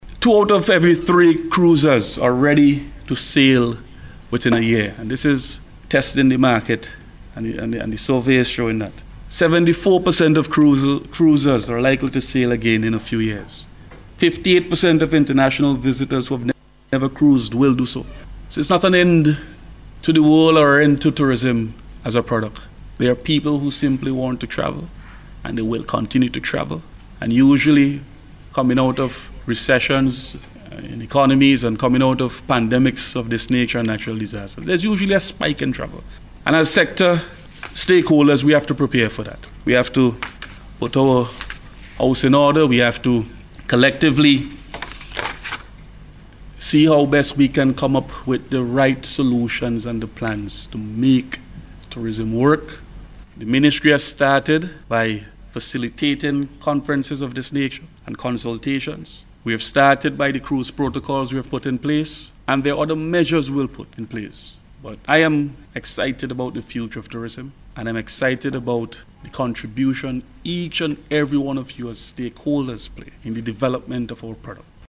Minister James gave the advice, as he addressed the opening of a Virtual Tourism Conference, hosted by the Ministry of Tourism last week.